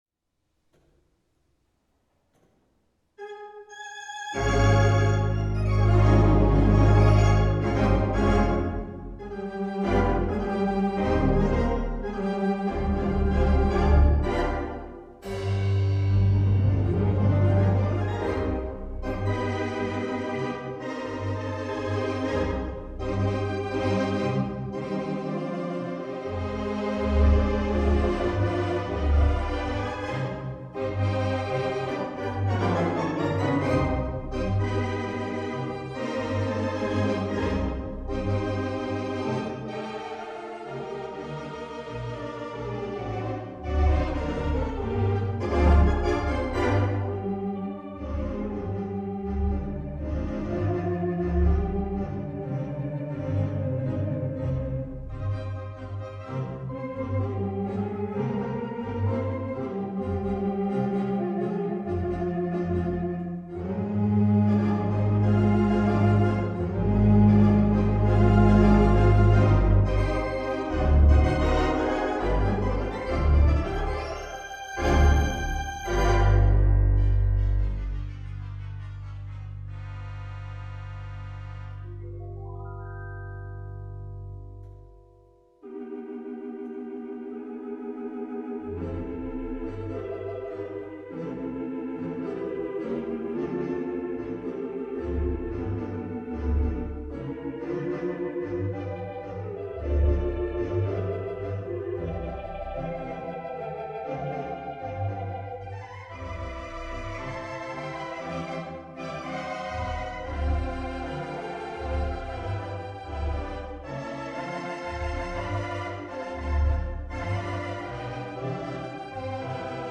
Organo da teatro americano
Temperamento equabile